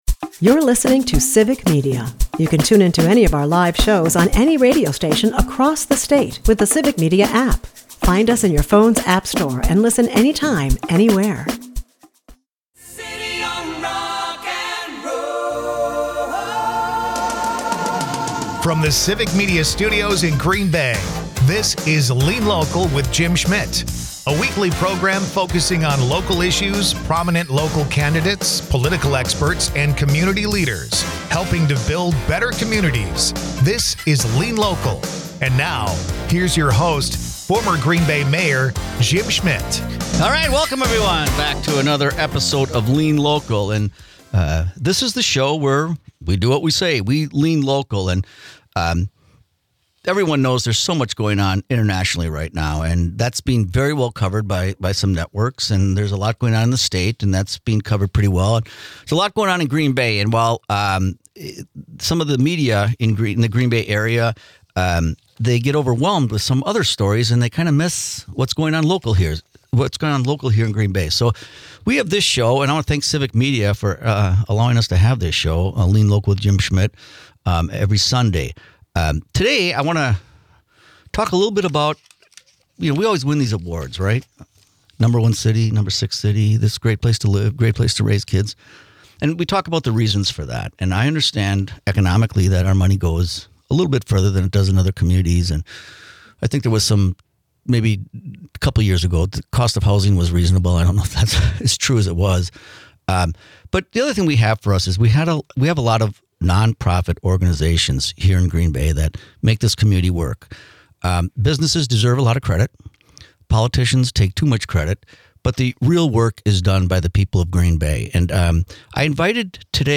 Dive into the heart of community issues with 'Lean Local,' hosted by former Green Bay Mayor Jim Schmitt. This refreshing political and issues program bypasses traditional left-versus-right rhetoric. Instead, it 'leans local' with insightful discussions and grassroots solutions, focusing on what truly matters in our neighborhoods and communities.